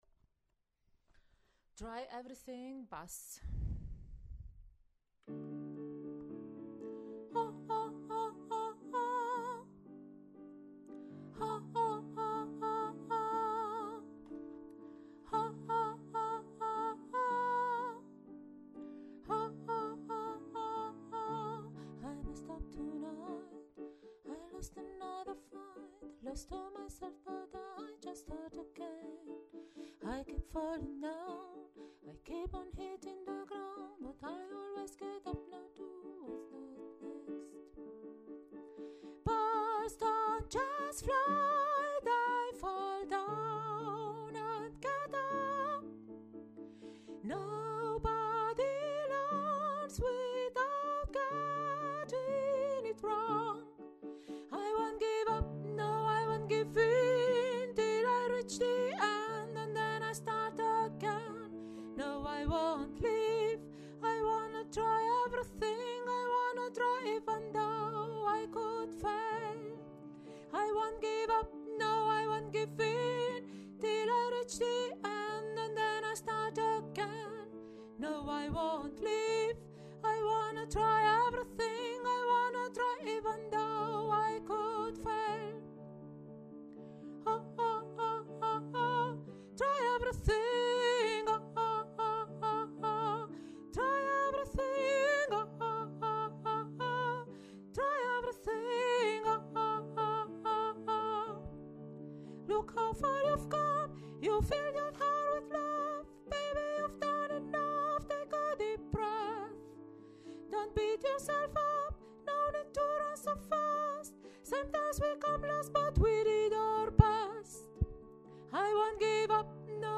Try Everything – Bass
Try-everything-Bass.mp3